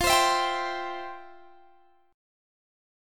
Listen to FM7sus2 strummed